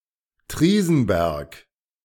Triesenberg (Swiss Standard German pronunciation: [ˈtriːzn̩ˌbɛrɡ]